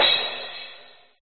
电子逆向碰撞 (3)
描述：数字反转镲片
Tag: 鼓数字化 碰撞转